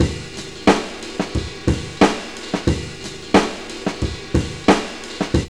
JAZZLP8 90.wav